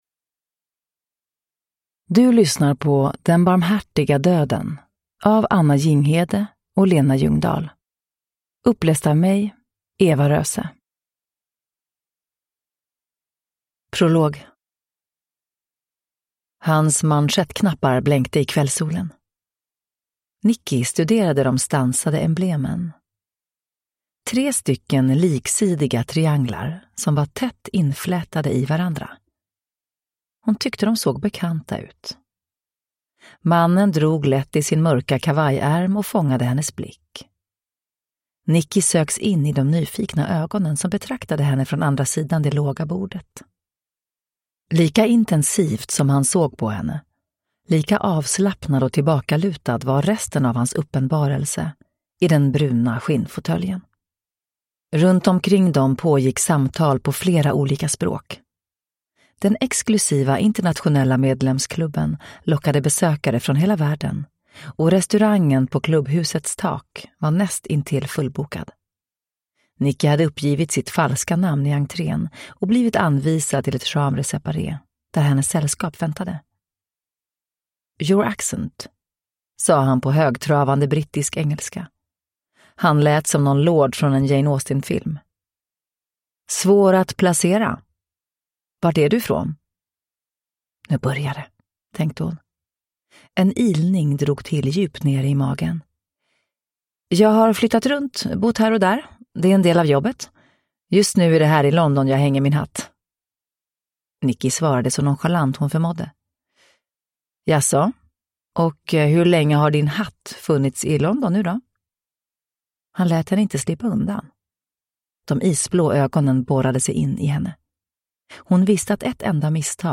Den barmhärtiga döden – Ljudbok
Uppläsare: Eva Röse